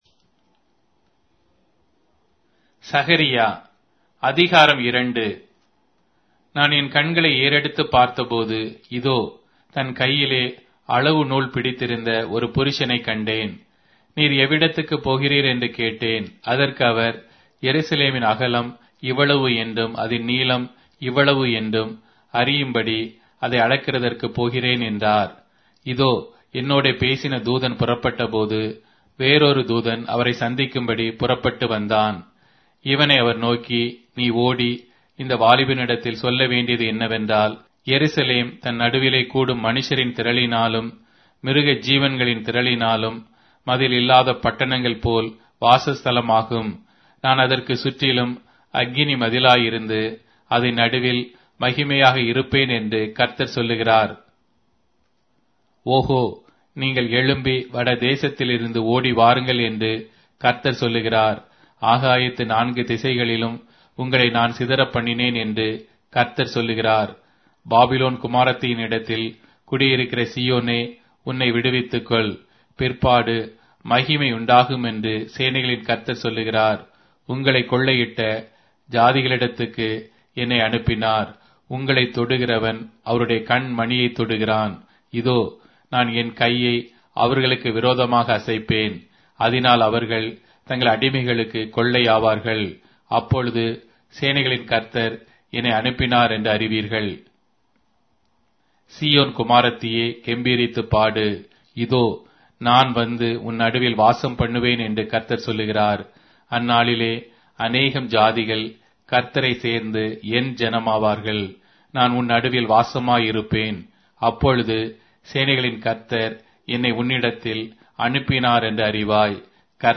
Tamil Audio Bible - Zechariah 5 in Rv bible version